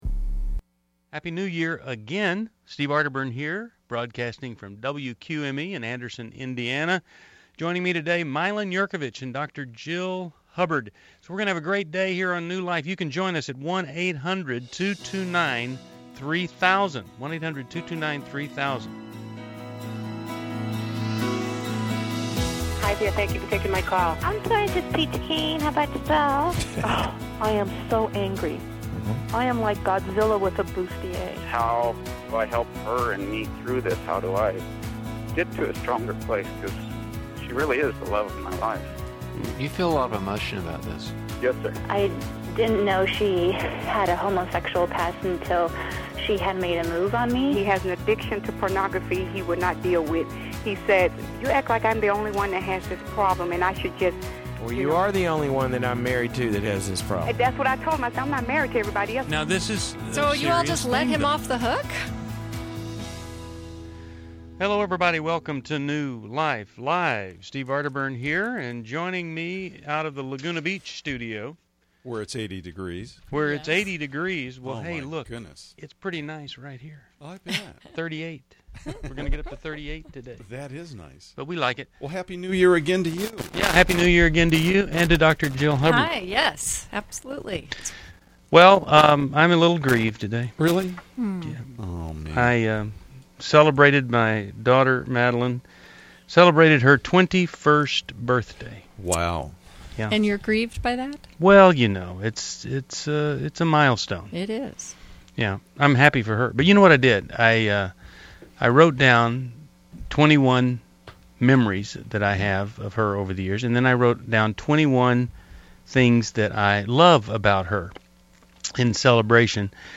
Explore relationship struggles and personal healing on New Life Live: January 4, 2012, as hosts tackle marriage issues, teen insecurities, and secrets.
Caller Questions: Since I was saved 5 yrs ago, my husband and I have grown apart.